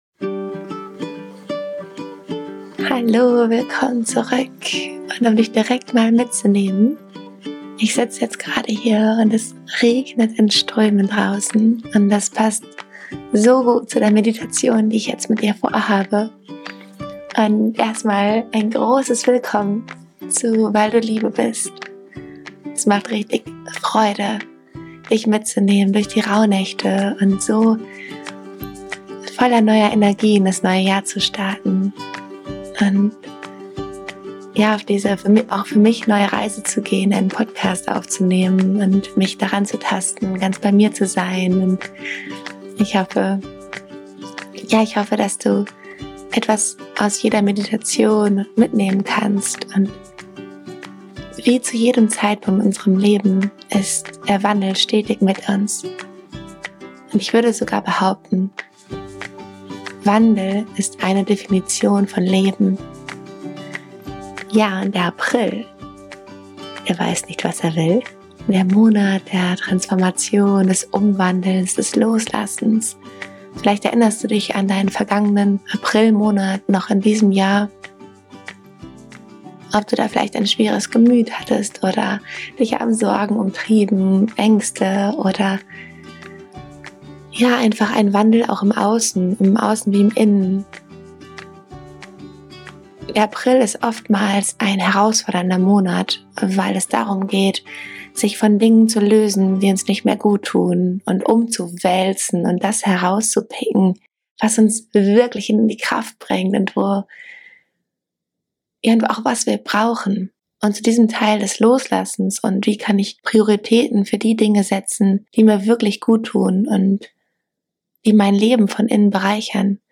Lade in dieser beruhigenden Meditation die Kraft des Loslassens ein.
Ich führe dich durch eine innere Reise, in der Regen zu einer wundervollen Kraft wird.